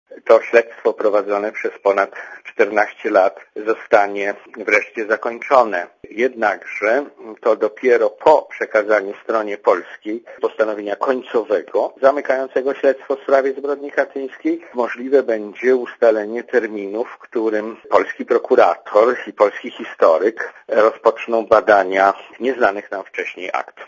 Mówi profesor Witold Kulesza, szef pionu śledczego IPN